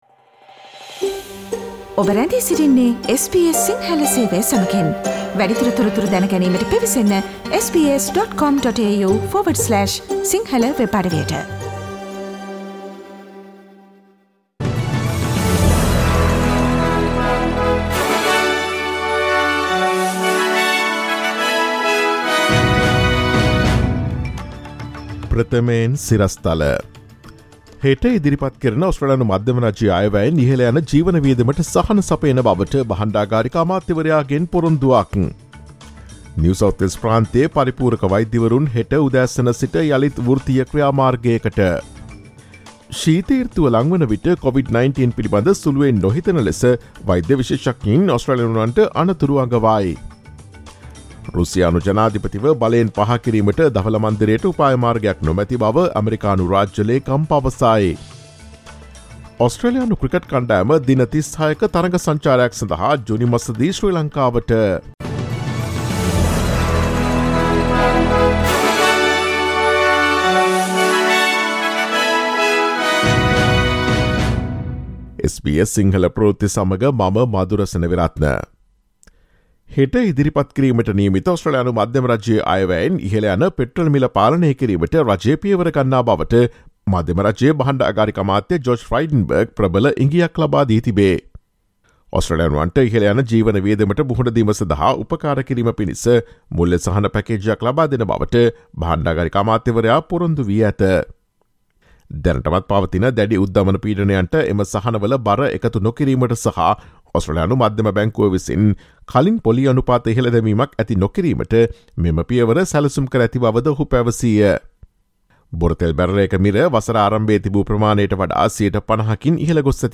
ඔස්ට්‍රේලියාවේ නවතම පුවත් මෙන්ම විදෙස් පුවත් සහ ක්‍රීඩා පුවත් රැගත් SBS සිංහල සේවයේ 2022 මාර්තු 28 වන දා සඳුදා වැඩසටහනේ ප්‍රවෘත්ති ප්‍රකාශයට සවන් දීමට ඉහත ඡායාරූපය මත ඇති speaker සලකුණ මත click කරන්න.